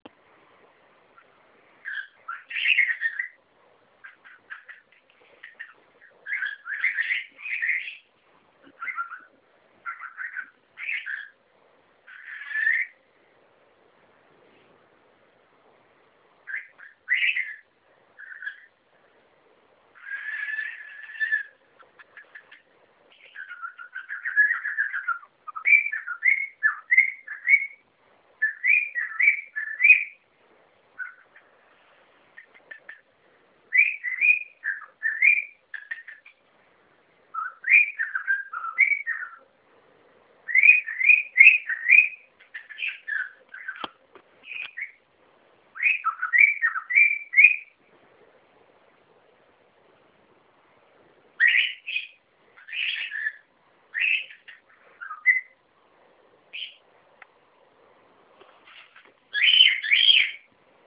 Balzende Hähne
Nymphensittichhähne können sehr unterschiedliche Gesänge entwickeln.
singenderhahn2.wav